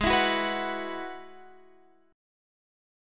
Abm7#5 chord